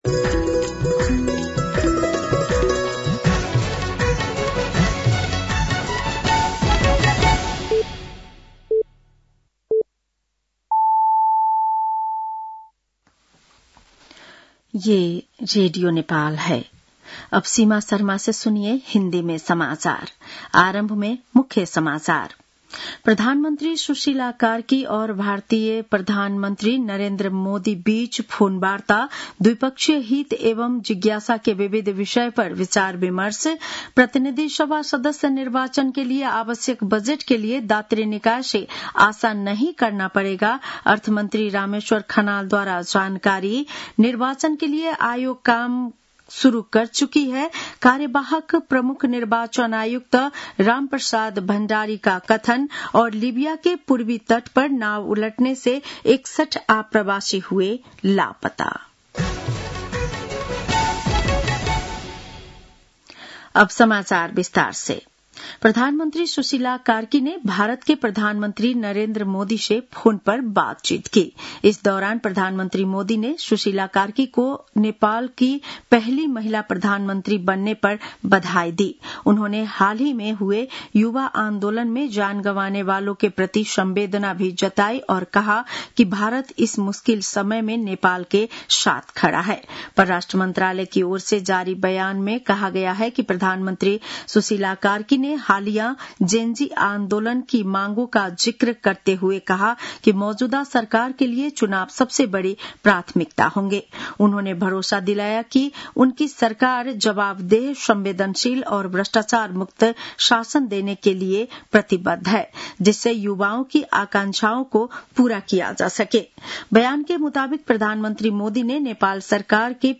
बेलुकी १० बजेको हिन्दी समाचार : २ असोज , २०८२
10-PM-Hindi-NEWS-6-2.mp3